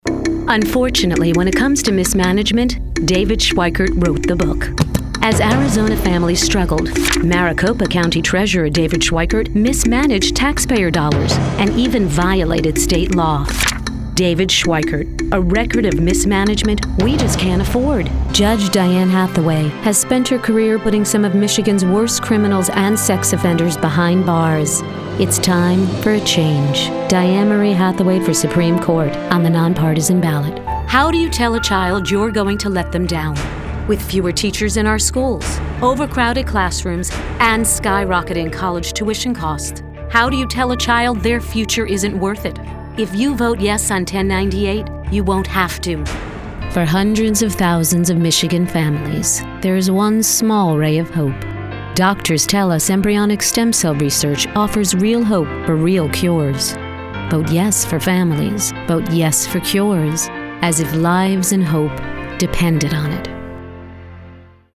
DEMOS Political Listen/Download Narration Listen/Download ATTRIBUTES female, Democrat, warm, friendly, millennial, positive, bright, audition copy